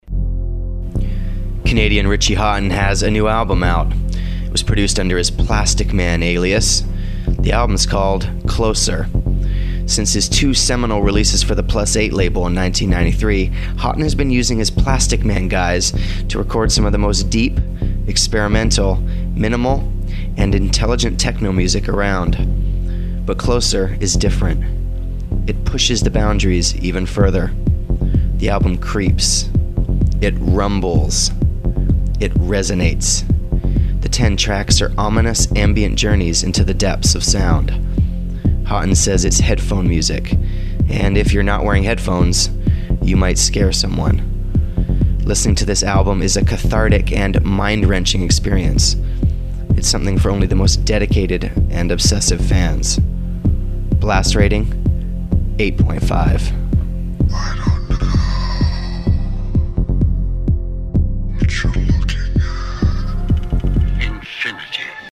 A Lowsound Music Review_